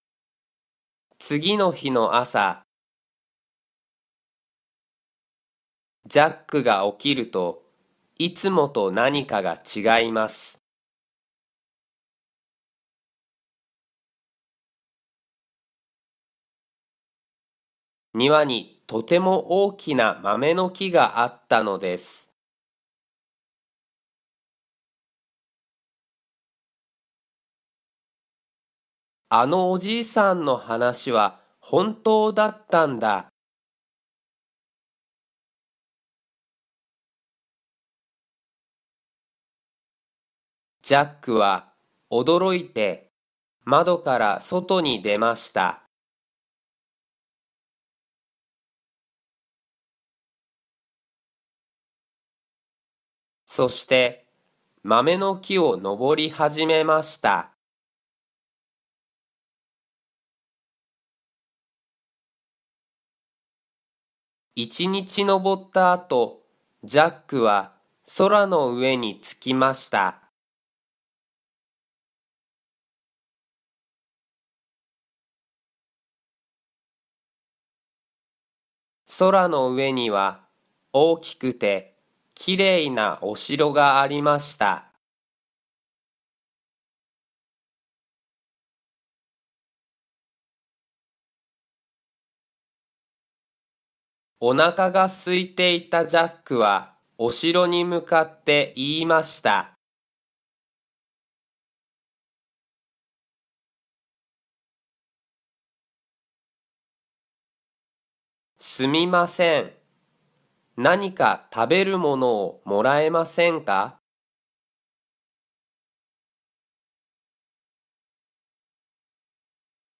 Japanese Graded Readers: Fairy Tales and Short Stories with Read-aloud Method
Slow Speed with Pauses